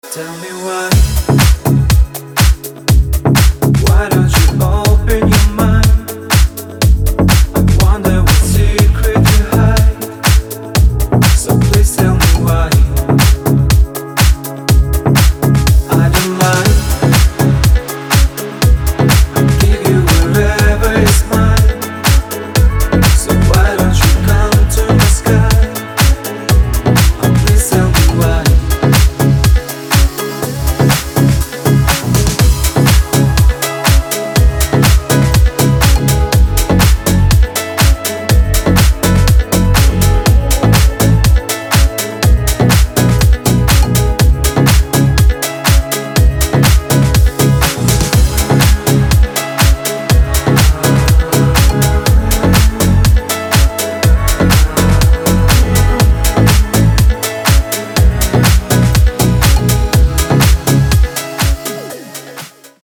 • Качество: 256, Stereo
мужской вокал
deep house
Electronic
клавишные
nu disco
Indie Dance